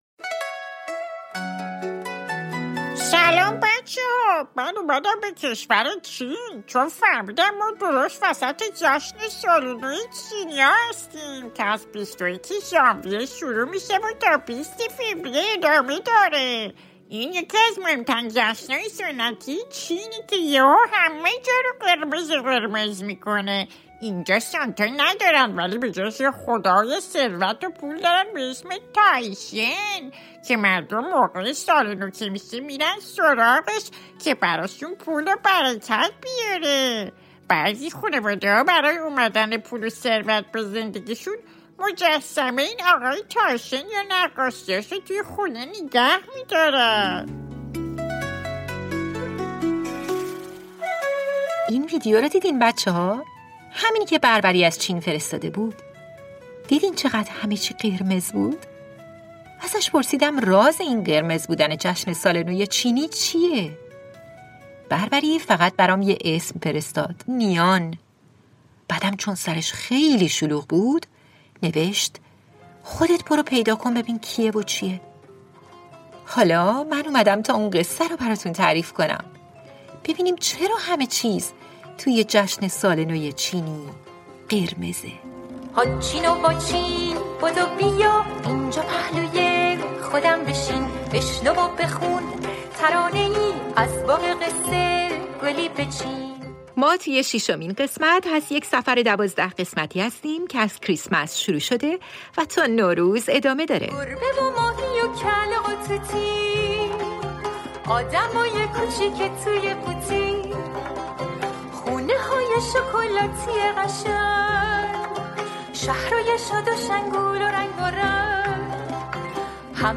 پادکست «هاچین واچین» اولین کتاب صوتی رادیو فردا، مجموعه داستان‌های کودکان است.